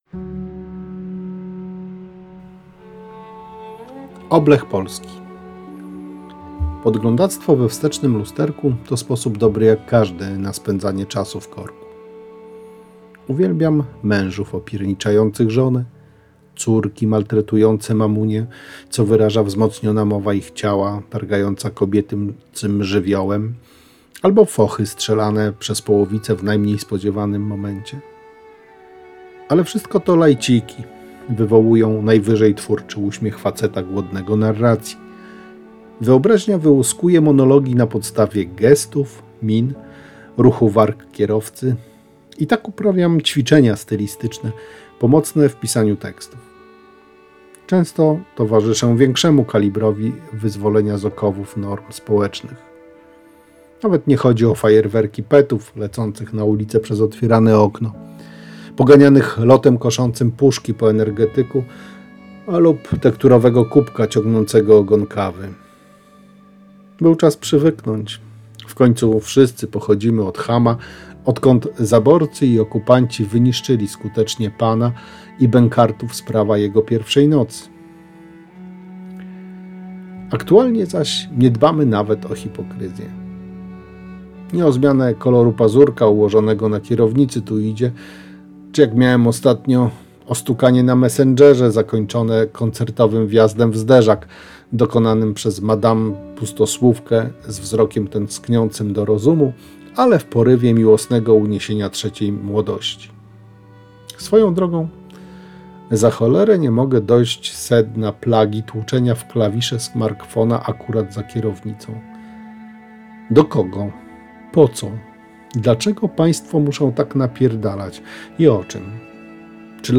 ❖Posłuchaj w interpretacji autora❖ Podglądactwo we wstecznym lusterku, to sposób dobry jak każdy, na spędzanie czasu w korku.